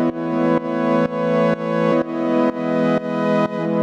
GnS_Pad-dbx1:4_125-E.wav